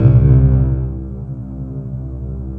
SAILOR M C2.wav